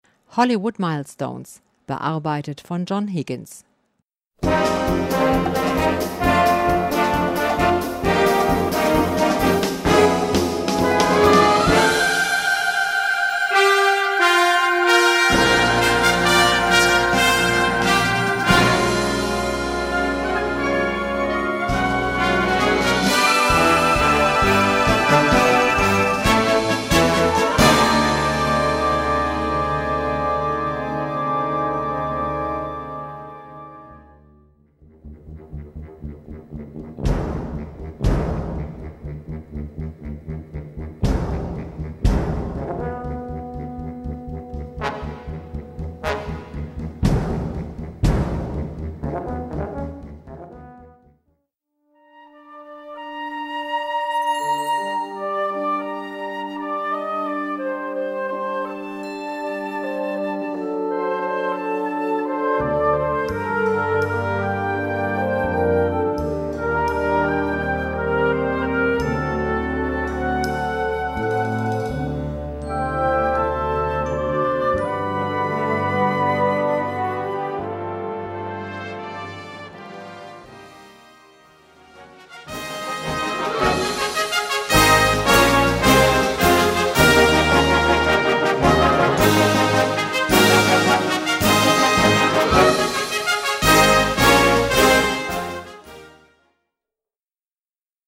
Gattung: Hollywood-Medley
Besetzung: Blasorchester